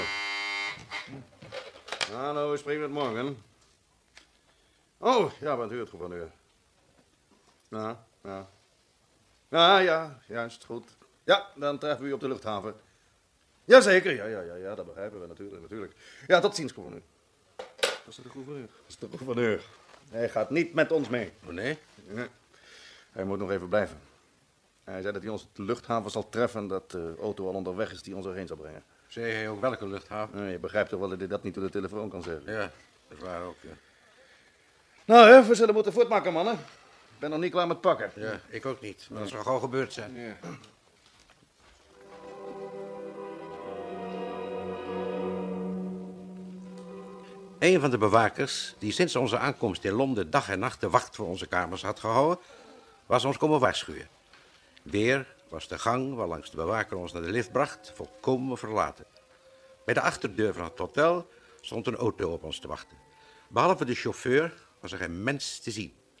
Als onze vrienden op het punt staan van het hotel per taxi naar het vliegveld te gaan, klopt er in de Britse versie daadwerkelijk een bewaker aan. In de Nederlandse doet de dokter hiervan ‘slechts’ verslag in zijn dagboek.